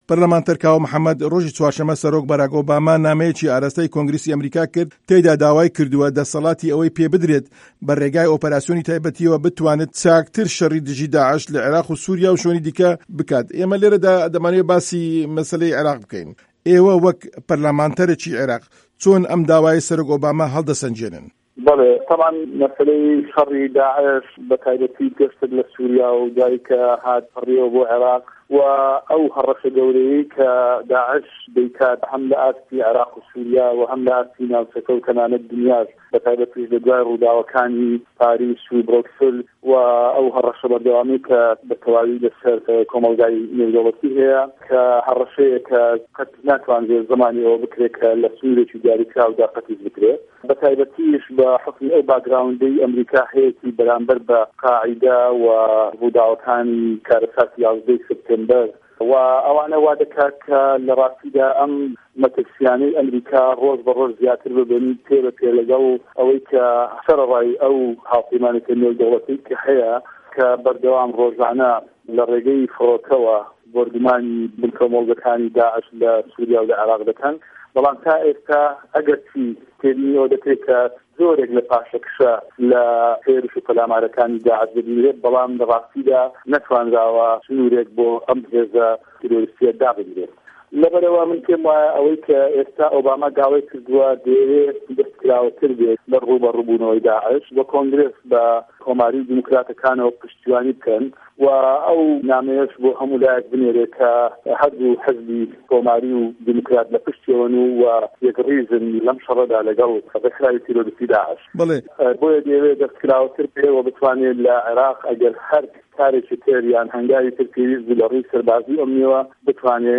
کاوه‌ محه‌مه‌د ئه‌ندامی په‌رله‌مانی عێراق له‌سه‌ر لیسـتی گۆڕان له‌ وتووێژێـکدا
وتووێژی کاوه‌ محه‌مه‌د